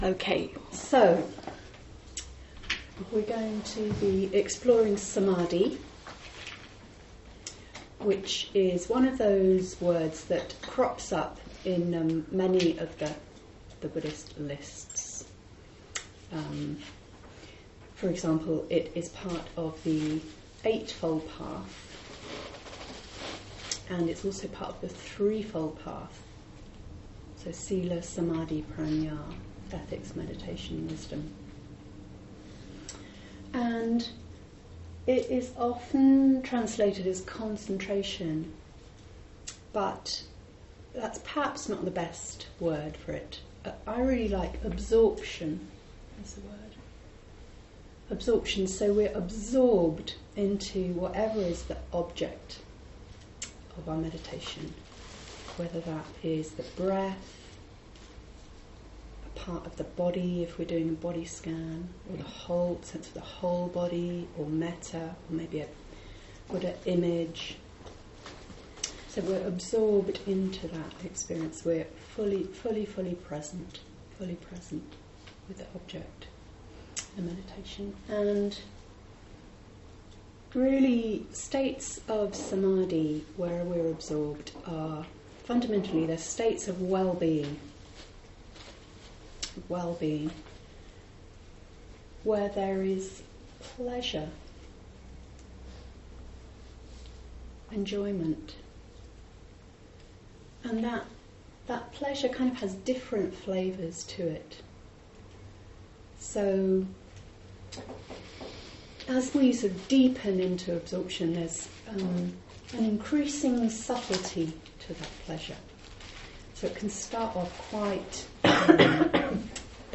Genre: Meditation.
SamadhiTalk.mp3